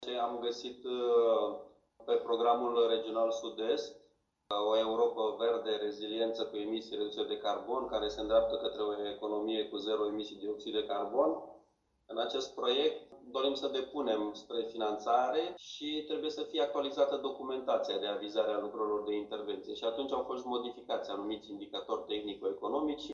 a venit cu explicații în ședința comisiilor de specialitate.